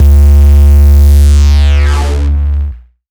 Sci Fi Hit.wav